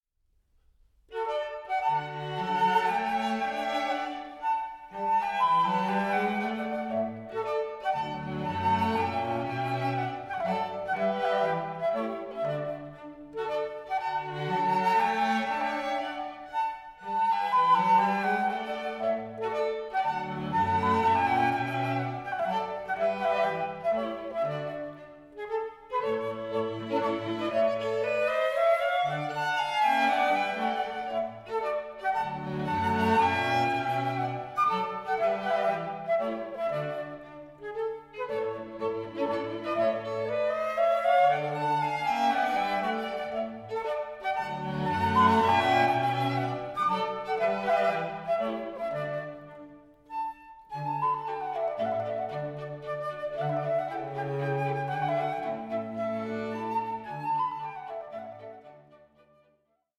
violin
viola
violoncello